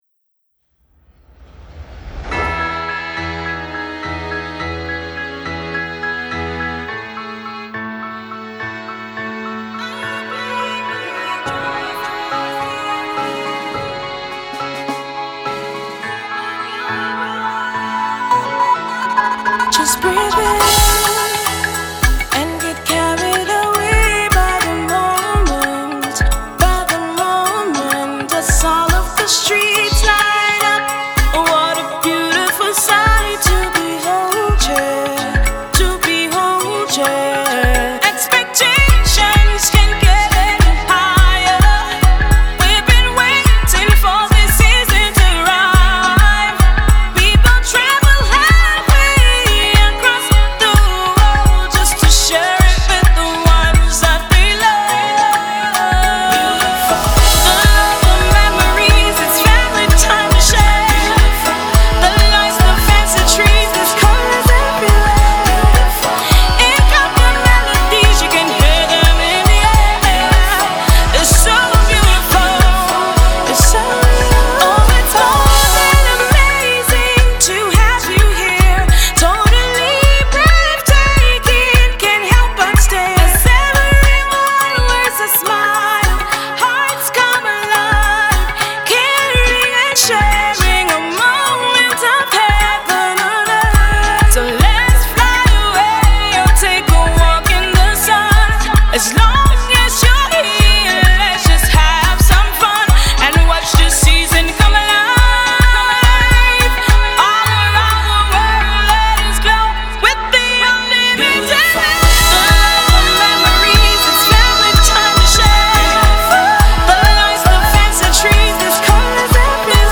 With her sonorous voice